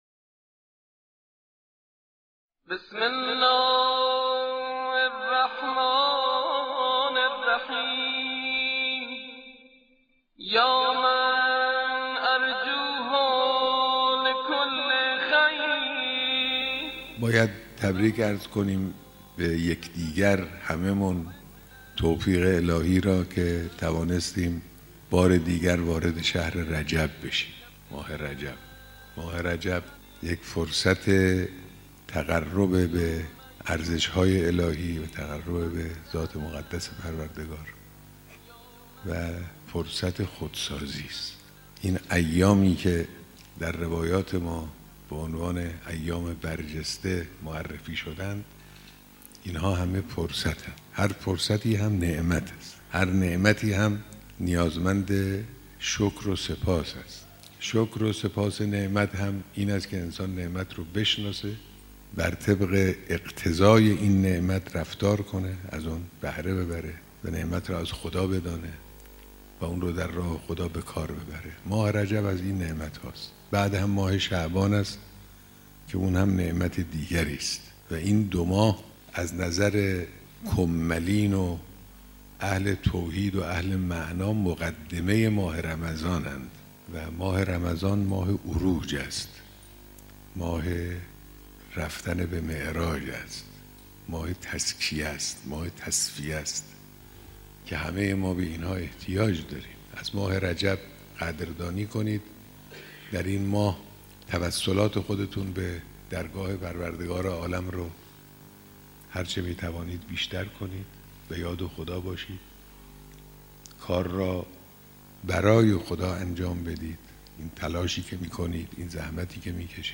صوت/ توصیه رهبرانقلاب در ماه رجب